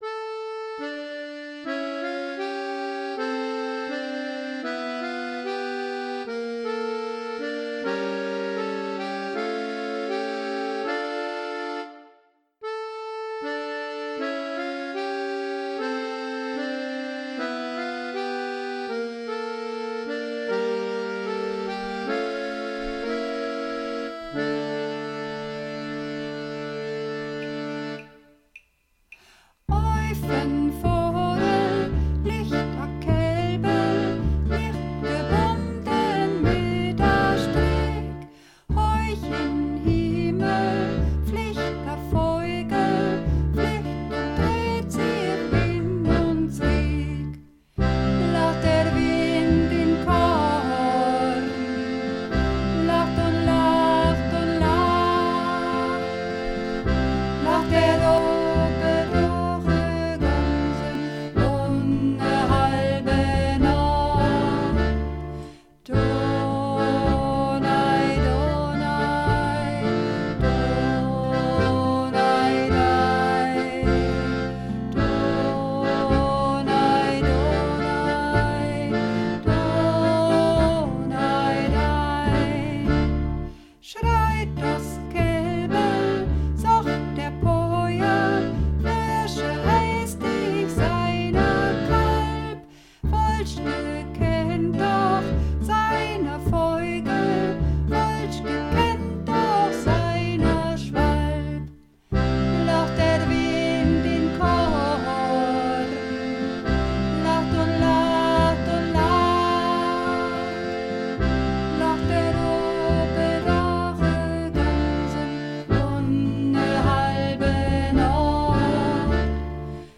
Runterladen (Mit rechter Maustaste anklicken, Menübefehl auswählen)   Dos Kelbl (Donaj Donaj) (Sopran)
Dos_Kelbl_Donaj_Donaj__3_Sopran.mp3